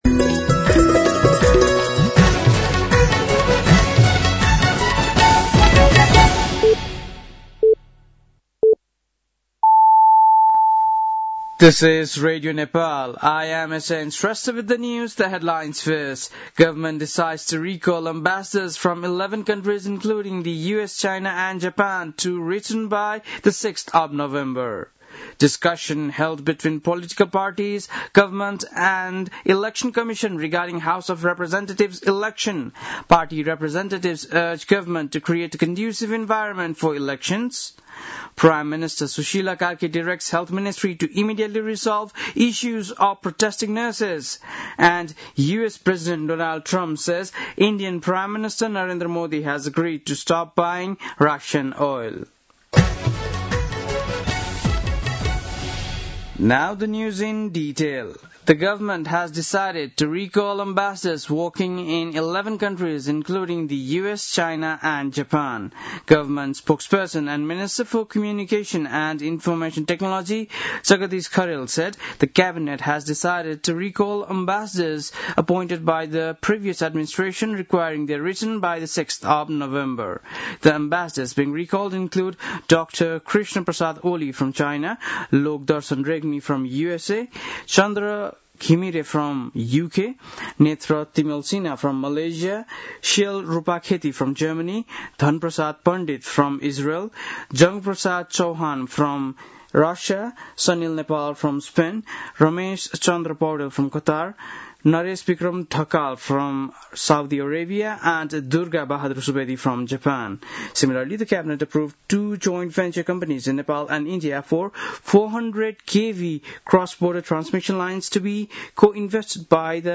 बेलुकी ८ बजेको अङ्ग्रेजी समाचार : ३० असोज , २०८२
8-pm-english-news-.mp3